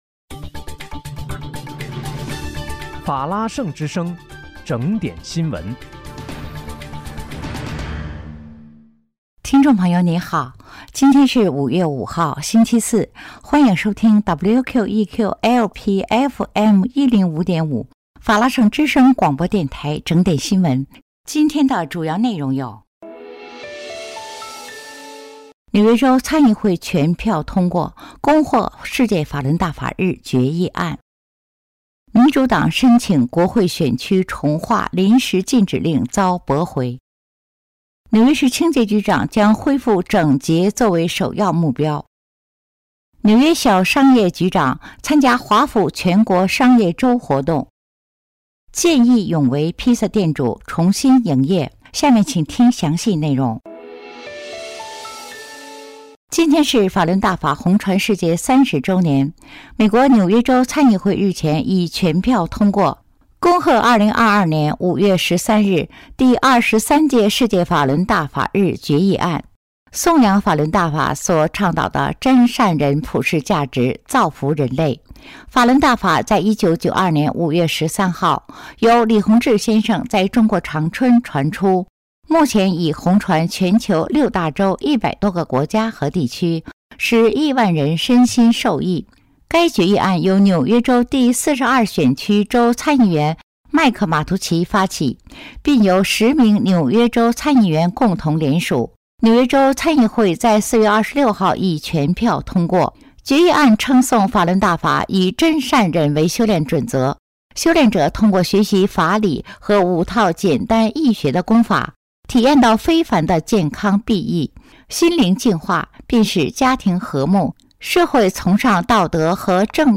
5月5日（星期四）纽约整点新闻